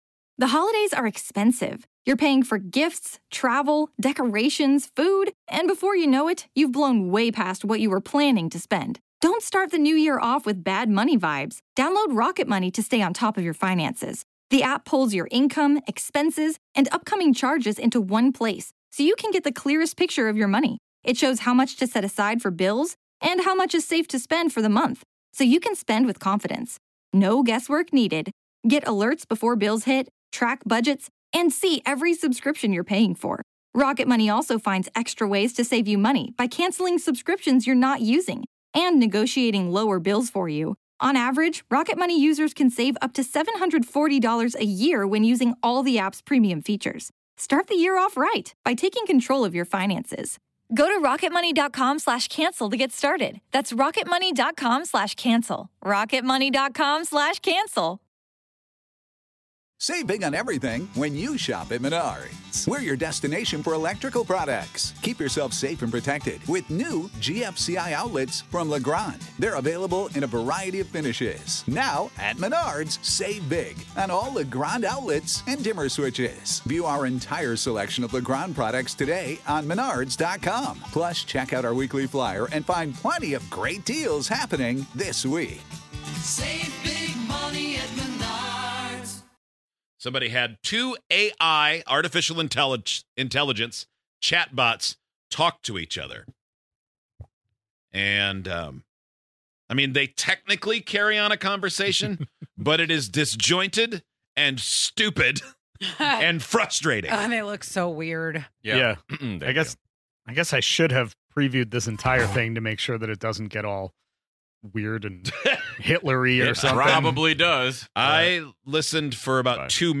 What Does A Conversation Between Two AI Bots Sound Like?